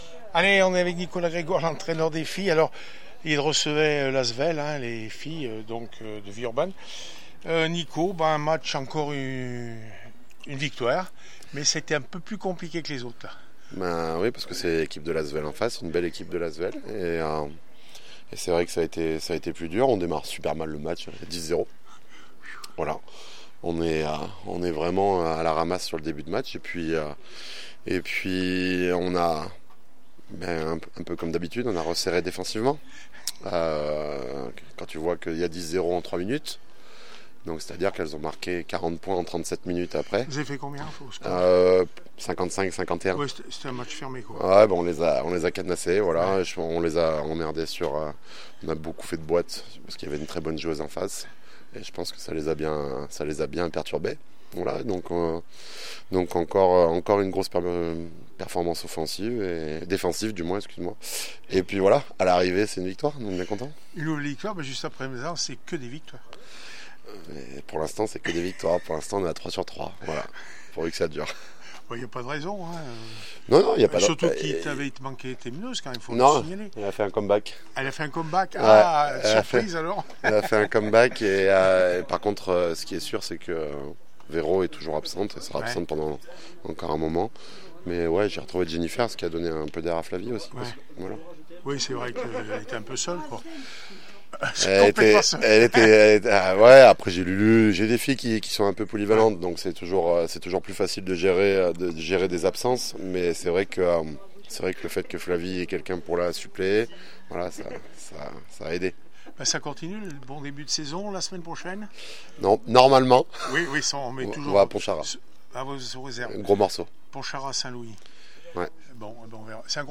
basket prenat fille aura asmb le puy 55-51 Villeurbanne réac après match 131020